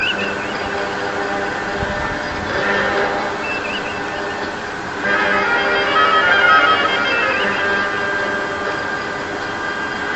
Dramatic type music